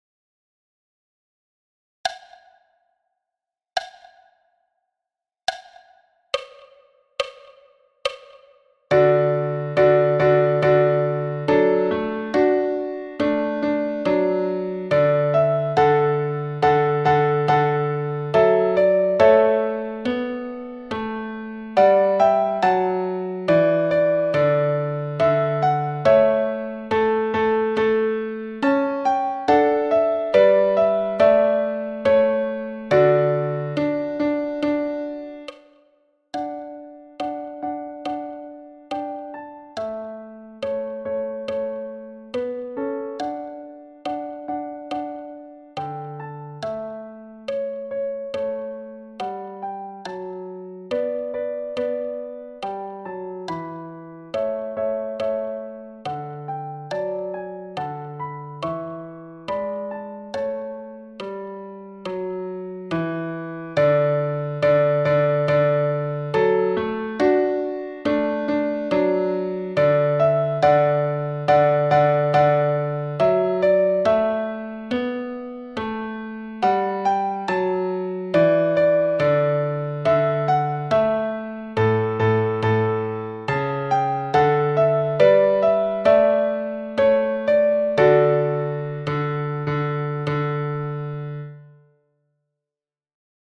Marche-piano-solo-a-70-bpm-clic-pp-2
Marche-piano-solo-a-70-bpm-clic-pp-2.mp3